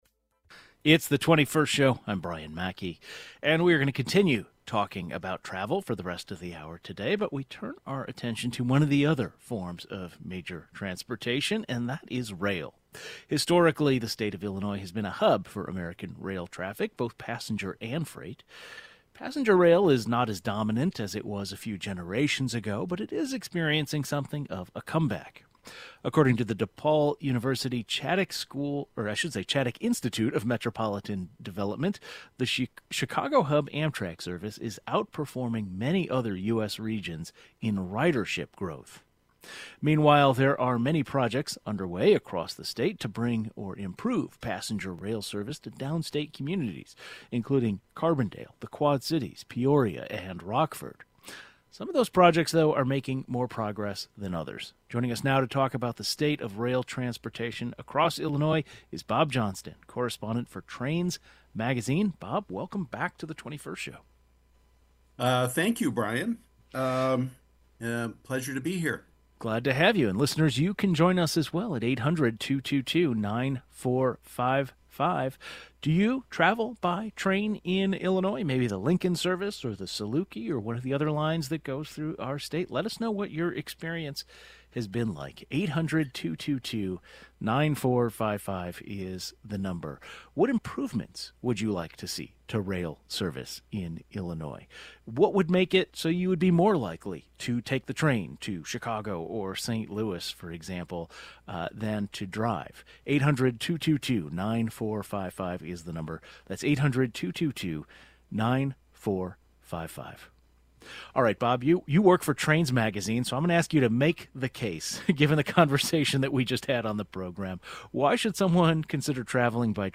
There's a lot of passenger rail projects underway across Illinois with some pushing full-steam ahead and some in railway limbo. The 21st Show is Illinois' statewide weekday public radio talk show, connecting Illinois and bringing you the news, culture, and stories that matter to the 21st state.